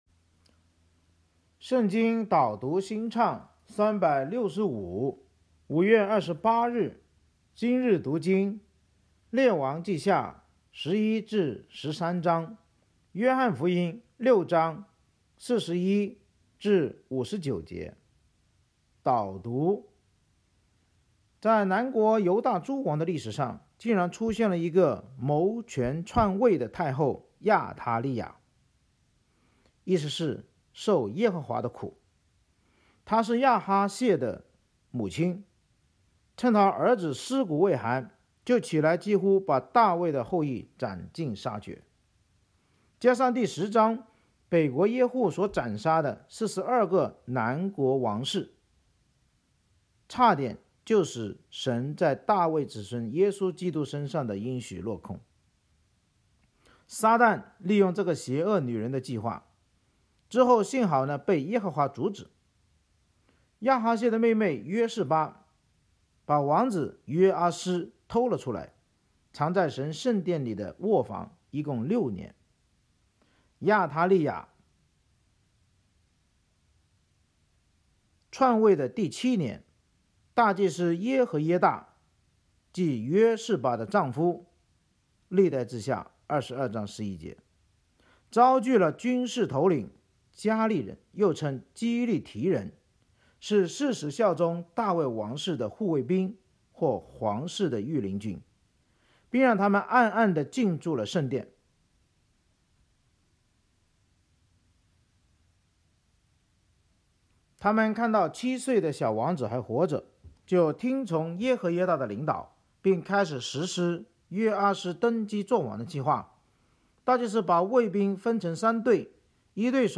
【经文朗读】（中文）